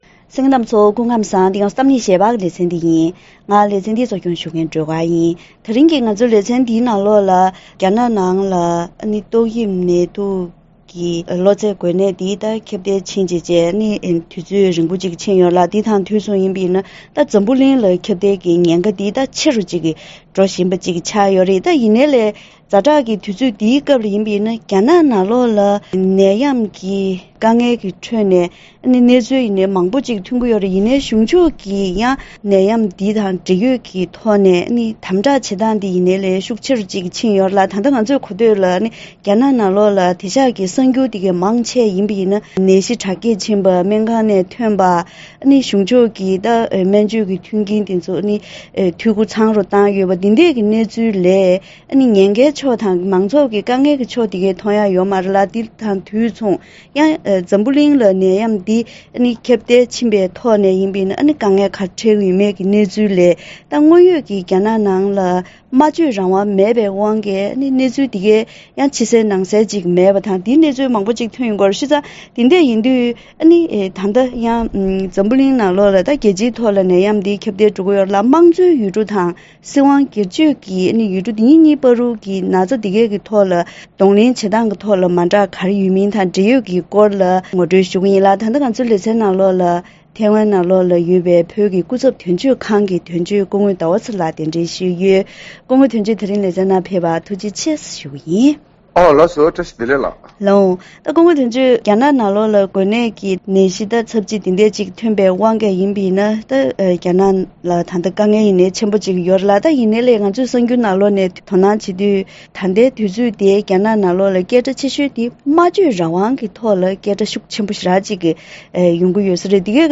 དེ་རིང་གི་གཏམ་གླེང་ཞལ་པར་ལེ་ཚན་ཏོག་དབྱིབས་ནད་དུག་གི་གློ་ཚད་འགོས་ནད་འདི་རྒྱ་ནག་ནང་ཁྱབ་གདལ་འགྲོ་དུས་གཞུང་ཕྱོགས་ཀྱི་གདོང་ལེན་བྱེད་སྟངས་དང་། ཡང་ཐེ་ཝན་དང་ལྷོ་ཀོ་རི་ཡའི་ནང་ལ་ཡང་འགོས་ནད་འདི་ཁྱབ་པའི་སྐབས་གཞུང་ཕྱོགས་ཀྱིས་ཕྱི་གསལ་ནང་གསལ་ངང་མཉམ་དུ་སྔོན་འགོག་གི་ལས་དོན་སྤེལ་བ་སོགས་ཀྱི་ཐོག་ནས་འབྲེལ་ཡོད་སྐོར་ལ་བཀའ་མོལ་ཞུས་པ་ཞིག་གསན་རོགས་གནང་།